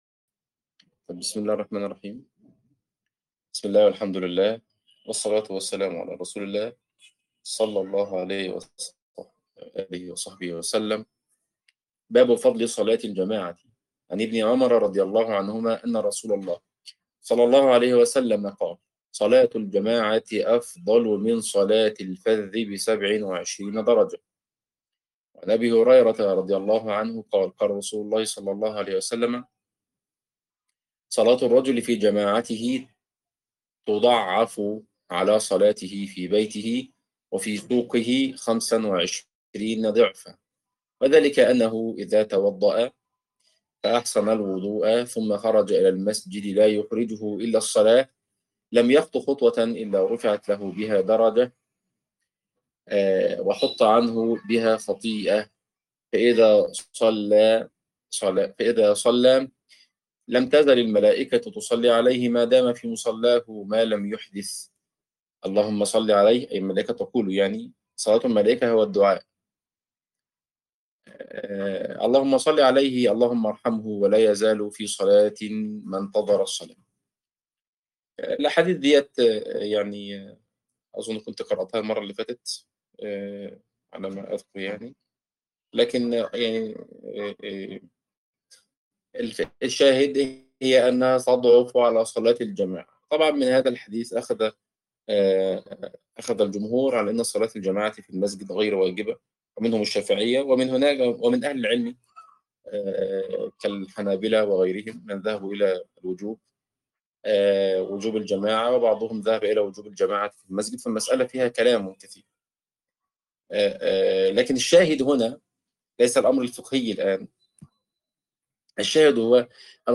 عنوان المادة الدرس 31 | دورة كتاب رياض الصالحين تاريخ التحميل الجمعة 27 يونيو 2025 مـ حجم المادة 45.52 ميجا بايت عدد الزيارات 208 زيارة عدد مرات الحفظ 86 مرة إستماع المادة حفظ المادة اضف تعليقك أرسل لصديق